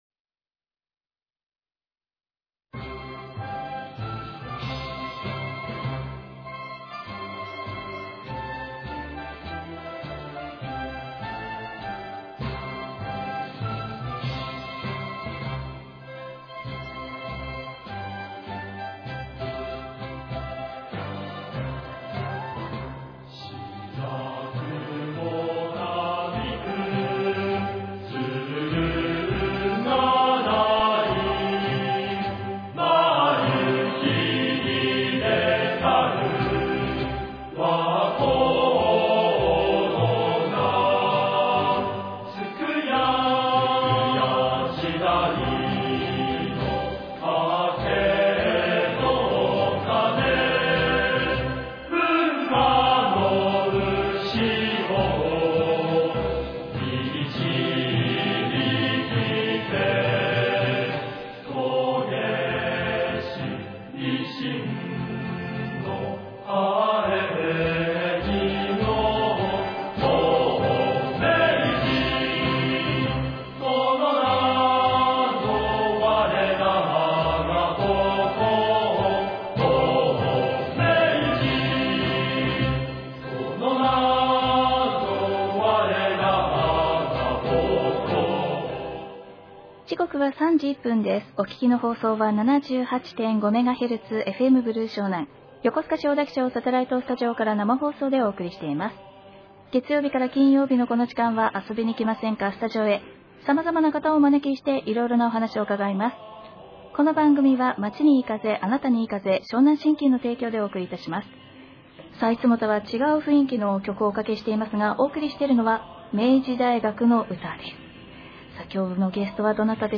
FMﾌﾞﾙｰ湘南(78.5MHz)にてﾏﾝﾄﾞﾘﾝｺﾝｻｰﾄCM開始！！！！【10月7日（月）には、愛の生放送♪】